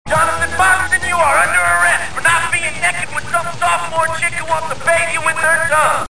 FILM QUOTES